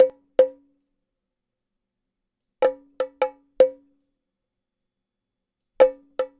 Bongo Fill 02.wav